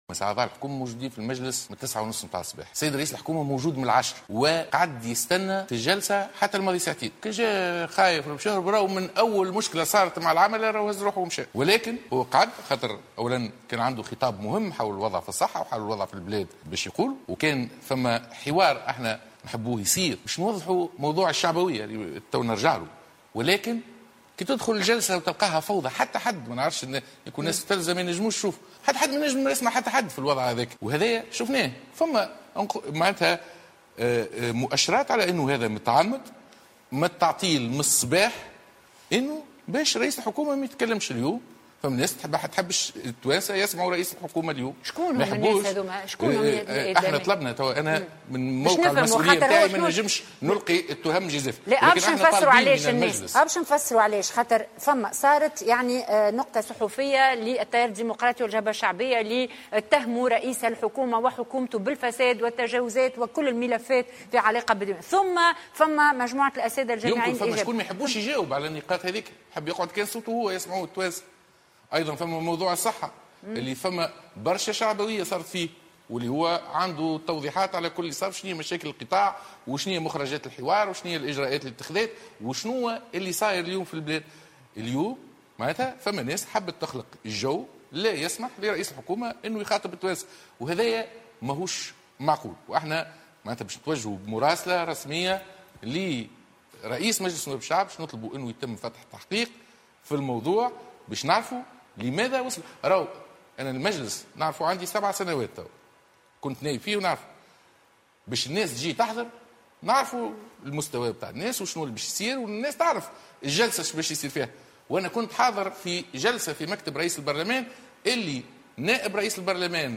قال الناطق باسم رئاسة الحكومة، إياد الدهماني إن تعطيل جلسة المساءلة مع رئيس الحكومة، يوسف الشاهد اليوم "متعمّد".